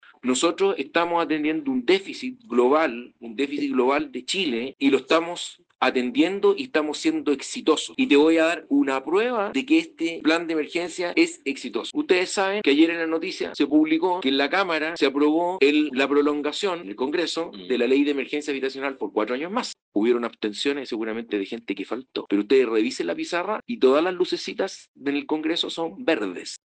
Así lo nformó a Manía el Seremi de Vivienda de la región de O’Higgins Luis Barboza quien estuvo en entrevista en el programa Café Express, donde aprovechó para defender el Plan de quienes lo cuestionaron durante su gestión, escuchemos: